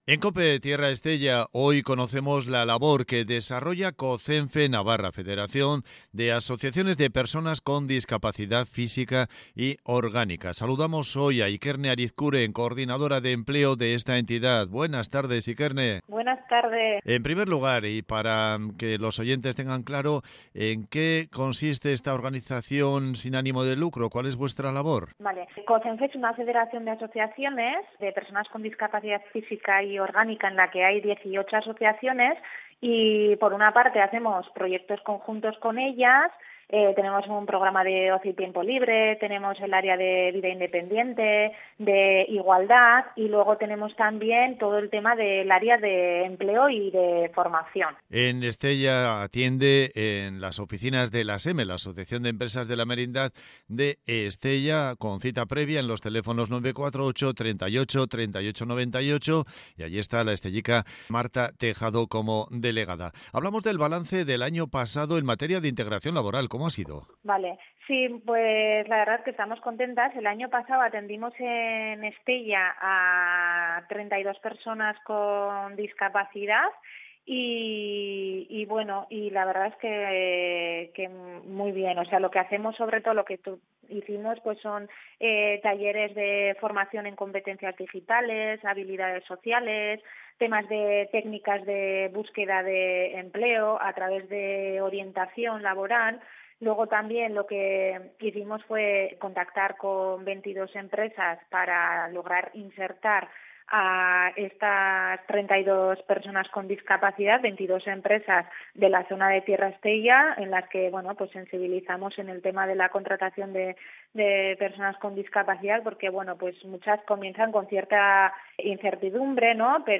> Potenciar la accesibilidad de las localidades de la zona y la integración laboral de mujeres con discapacidad, principales retos para este año . ESCUCHA LA ENTREVISTA EN COPE ESTELLA: